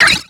Cri de Lovdisc dans Pokémon X et Y.